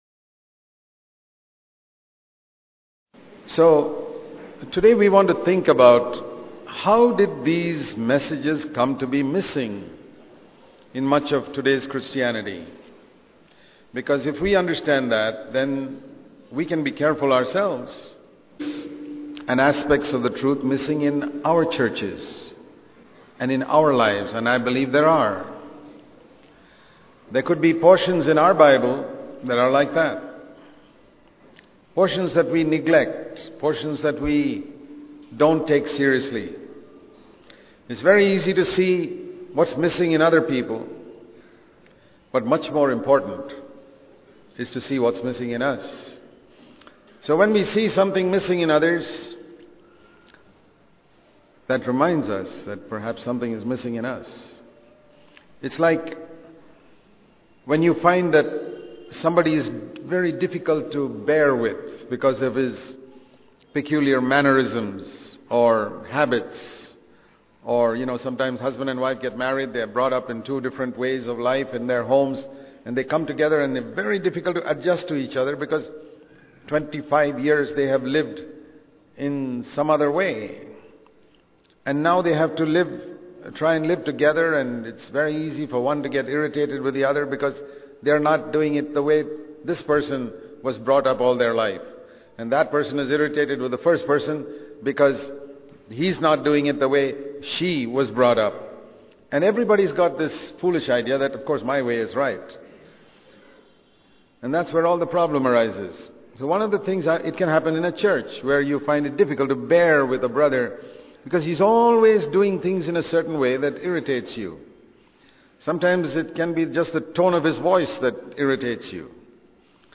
07.Being Protected From Deception, Ourselves The Missing Messages In Today's Christianity Bangalore Conference 2006 sermons.